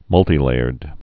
(mŭltē-lāərd, -tī-)